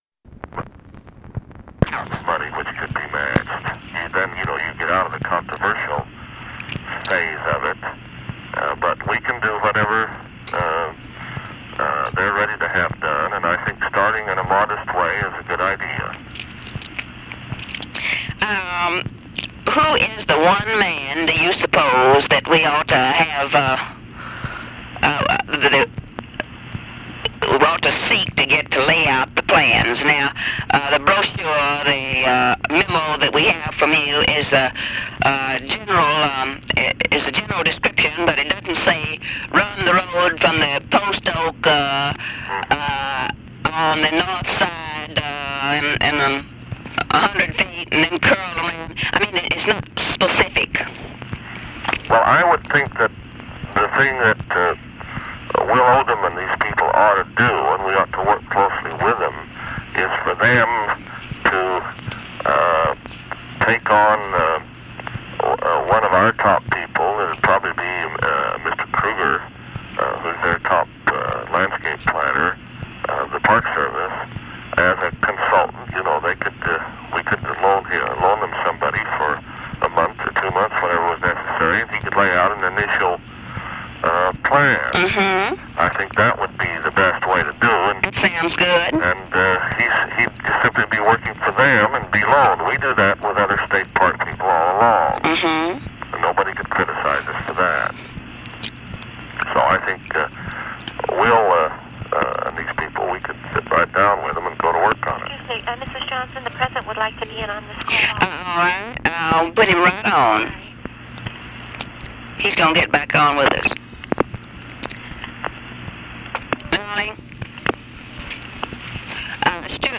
Conversation with STEWART UDALL, LADY BIRD JOHNSON and OFFICE SECRETARY, December 26, 1966
Secret White House Tapes